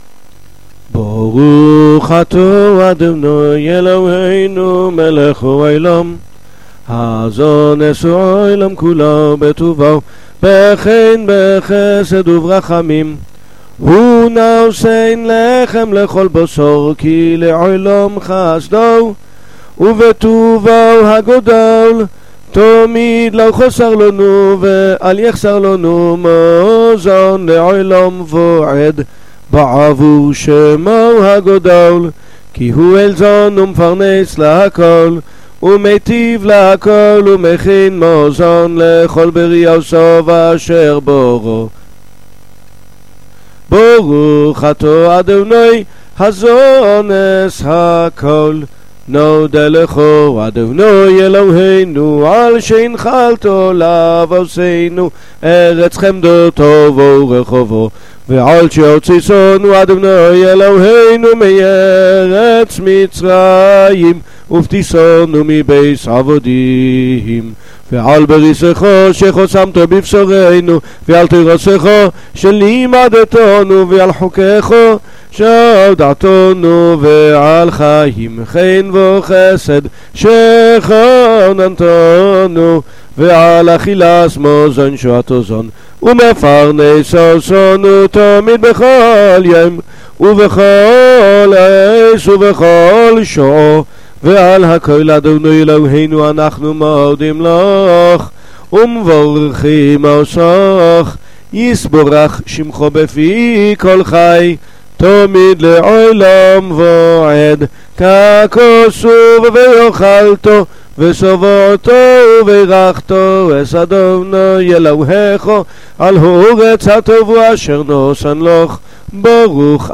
• AudioDie 4 Segenssprüche des Birkát haMasóns (Tischgebetes) in ashkenasicher Aussprache.
birkot_birkat_hamazon1.mp3